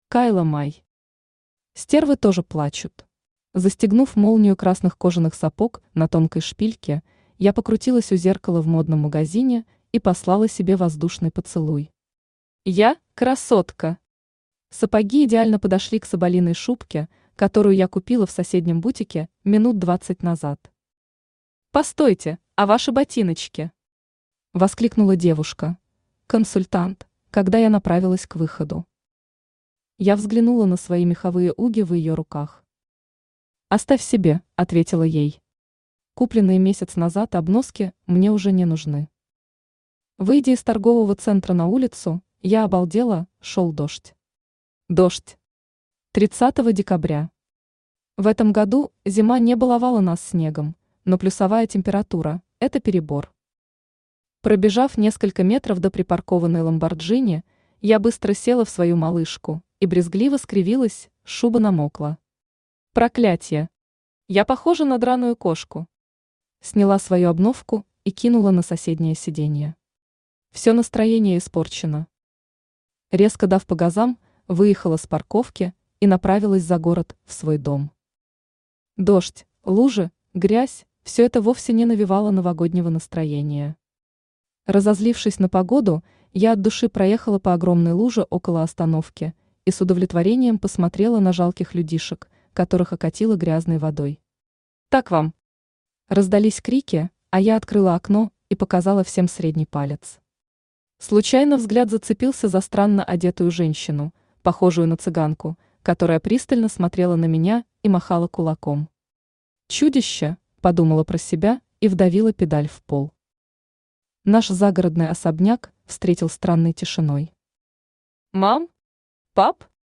Аудиокнига Стервы тоже плачут | Библиотека аудиокниг
Aудиокнига Стервы тоже плачут Автор Кайла Май Читает аудиокнигу Авточтец ЛитРес.